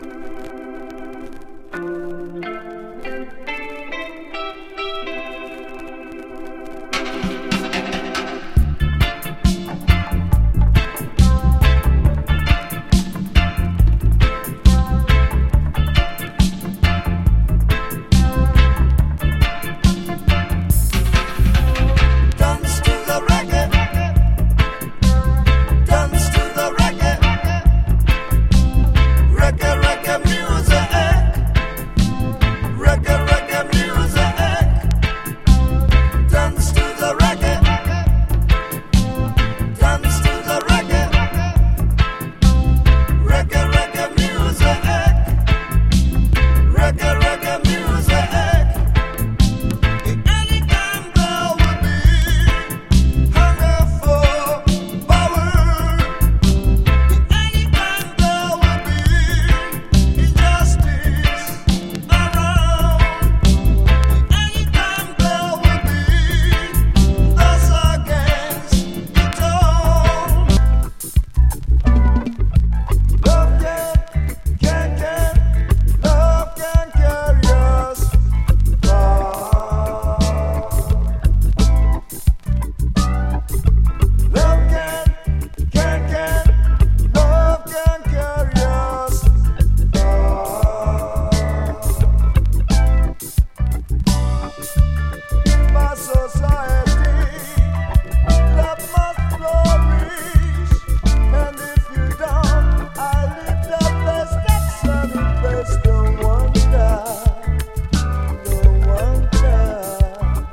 シンセ・メロウ
スムース・シティ・ソウル
本格派ディスコティーク